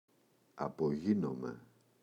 απογίνομαι [apo’γinome] – ΔΠΗ